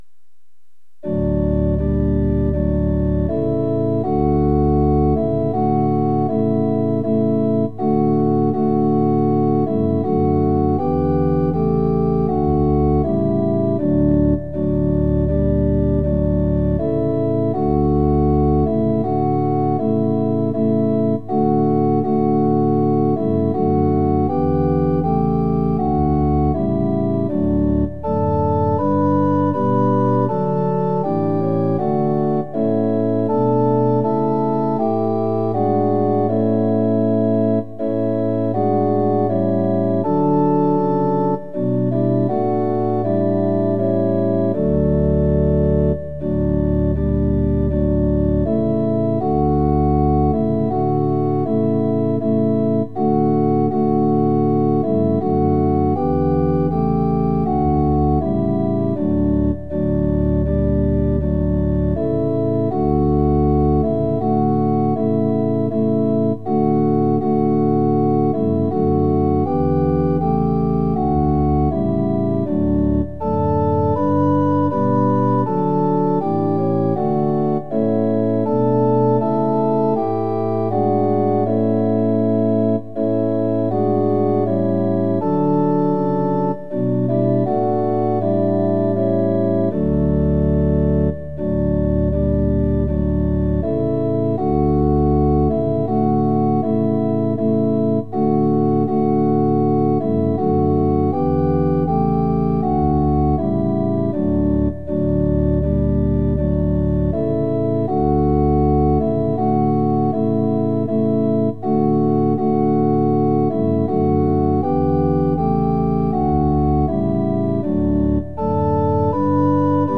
◆　４分の３拍子：　三拍目から始まります。